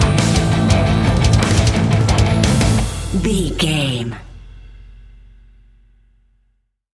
Epic / Action
Fast paced
Aeolian/Minor
guitars
heavy metal
Heavy Metal Guitars
Metal Drums
Heavy Bass Guitars